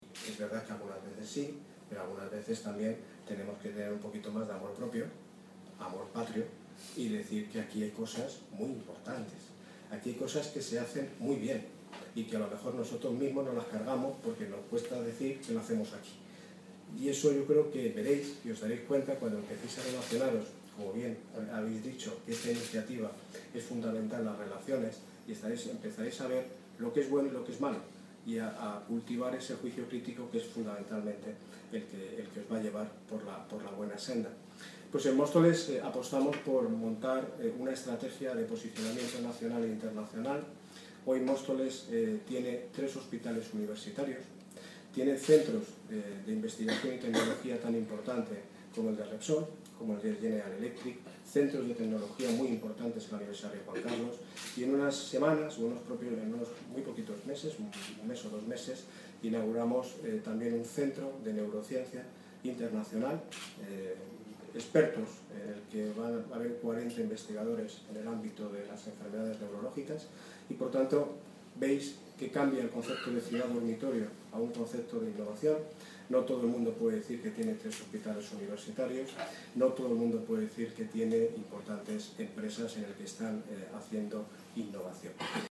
Audio - Daniel Ortiz (Alcalde de Móstoles) Sobre YUZZ